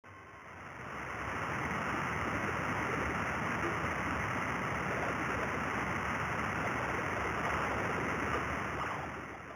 ¶ Wandering Line Description: Wandering line glitches look like a meandering line at high frequencies. They can be caused by motors (such as vacuum pumps) that do not have fixed frequencies, or by beats between higher frequency signals that have some frequency variation. Multiple wandering lines can be present at the same time but at different frequencies. Cause: Potentially related to a very slowly changing whistle / radio frequency beat, or equipment that does not operate at a fixed frequency.